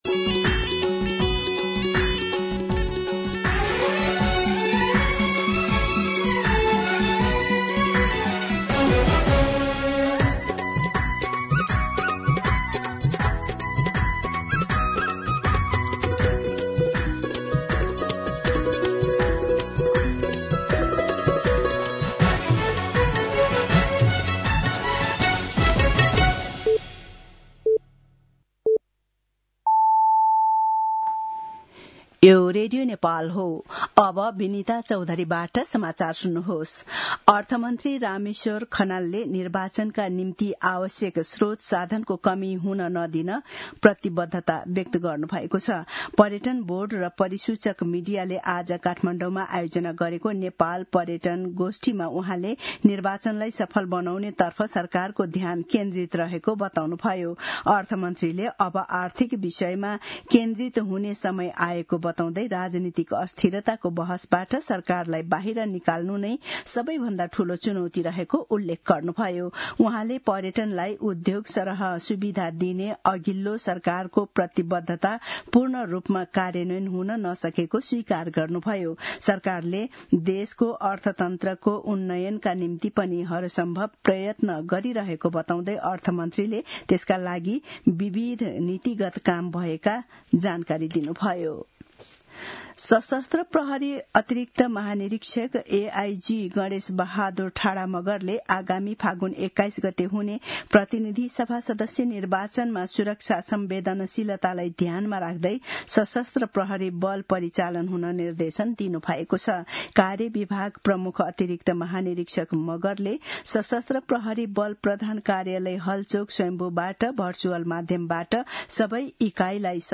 दिउँसो १ बजेको नेपाली समाचार : ८ फागुन , २०८२